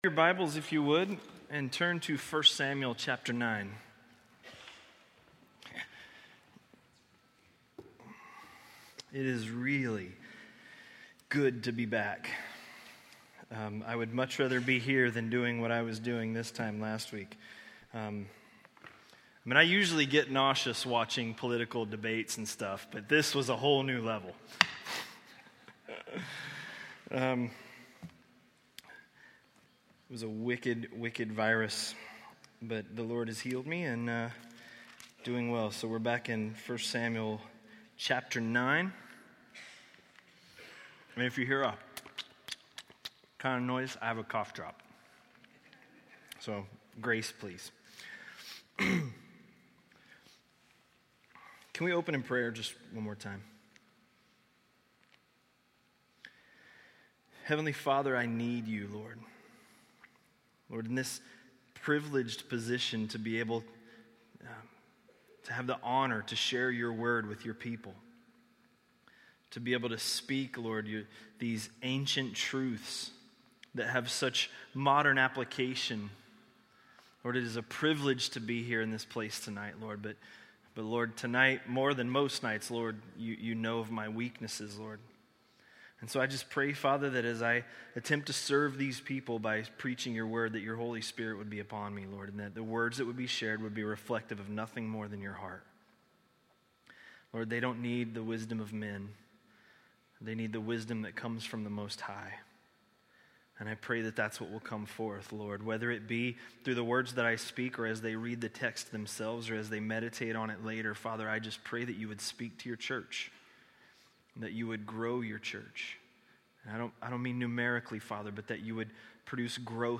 A message from the series "1 Samuel." 1 Samuel 9–10:16